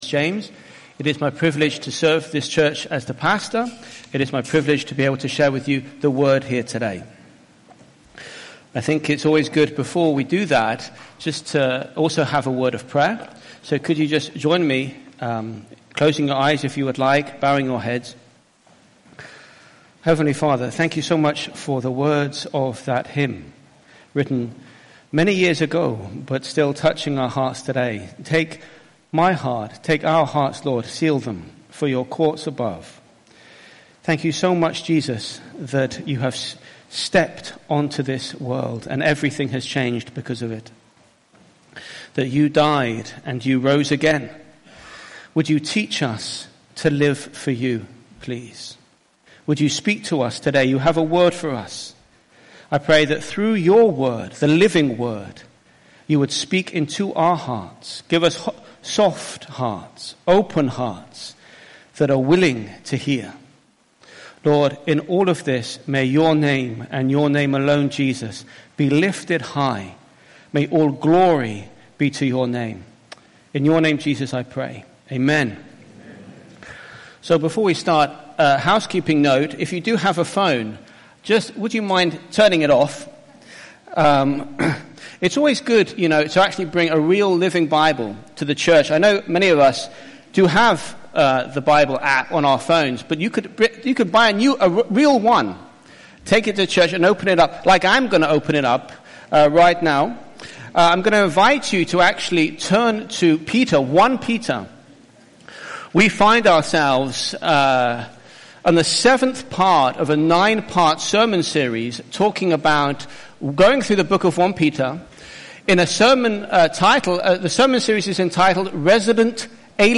IBC Hamburg Predigt